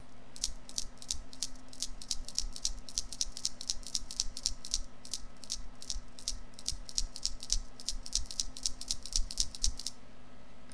Montage des Rattles (Ampoule de verre remplie de billes émettant un son plus ou moins grave)
Exemple de son produit par un rattle cliquez ici :arrow:
rattle.mp3